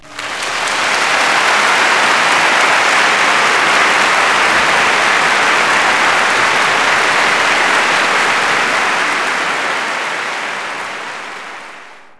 clap_032.wav